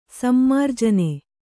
♪ sammarjane